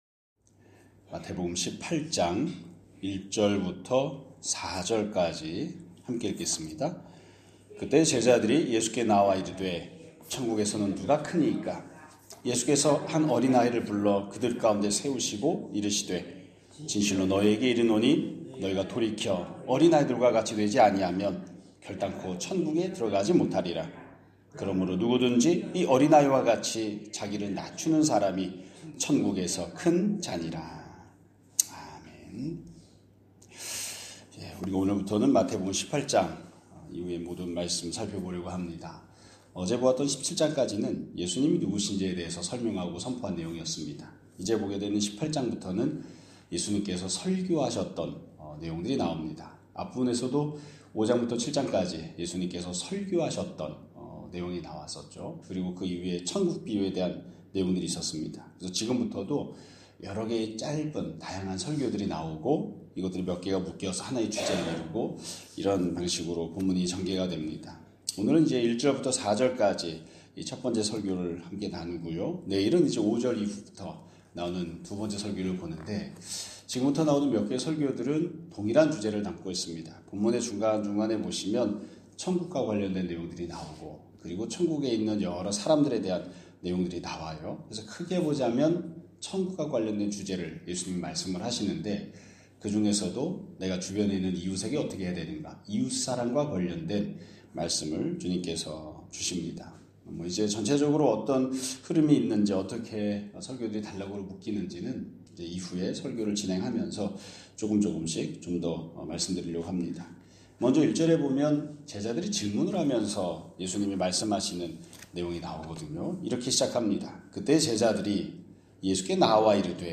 2025년 12월 3일 (수요일) <아침예배> 설교입니다.